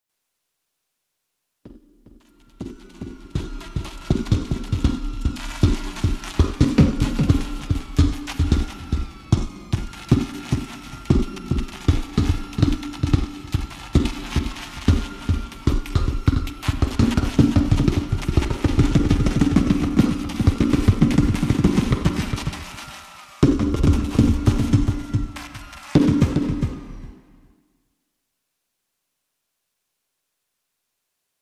花火の音真似 俺